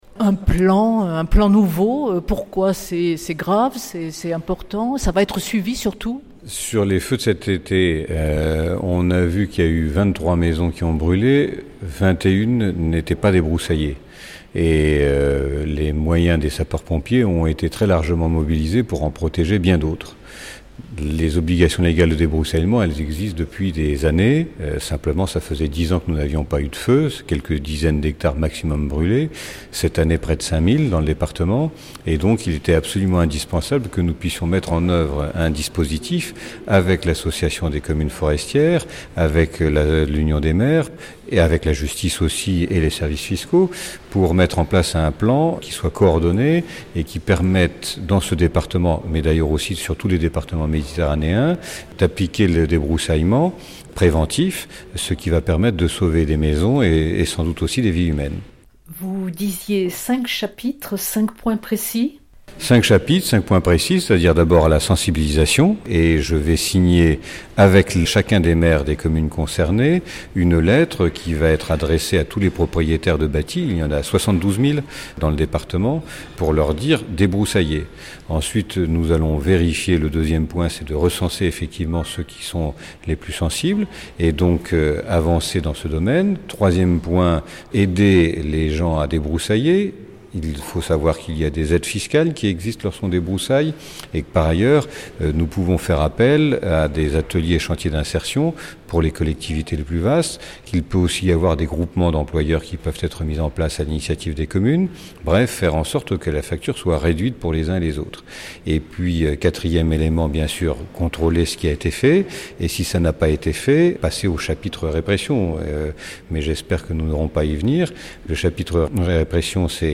Stéphane Bouillon, Préfet de la Région Provence-Alpes-Côte d’Azur, Préfet de la zone de défense et de sécurité Sud (les 15 départements de l’Arc Méditerranéen) et Préfet des Bouches-du-Rhône, vient de réunir des représentants des Communes et des Services concernés par la prévention des feux de forêt pour agir sur les obligations légales de débroussaillement.